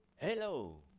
การอ่านข้อมูลเสียงจากโมดูลเซนเซอร์ INMP441 ด้วย ESP32 - IoT Engineering Education
ในการรับข้อมูลเสียงจากบอร์ด ESP32 ก็ใช้วิธีเขียนโค้ดด้วยภาษา Python เพื่อรับข้อมูลด้วยวิธี TCP หรือ UDP และนำข้อมูลที่ได้มาบันทึกลงไฟล์เสียง .wav ตามความยาวเสียงที่ต้องการ เช่น 1 วินาที และการบันทึกเสียงจะเริ่มขึ้น โดยมีเงื่อนไขของทริกเกอร์ เช่น เมื่อแอมพลิจูดของเสียง มีระดับสูงกว่าค่า TRIGGER_THRESHOLD ที่กำหนดไว้
รูป: ตัวอย่างการแสดงข้อมูลเสียงเป็นคลื่นสัญญาณที่มีความยาว 1 วินาที และ "สเปกโตรแกรม" (Spectrogram) ซึ่งได้จากเสียงพูดคำว่า